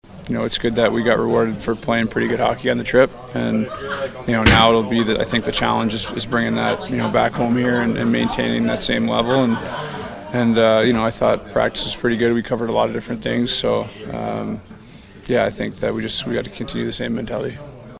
The Penguins put in an intense workout yesterday after taking Monday off.  Crosby says that’s the kind of effort they will need to make a playoff run.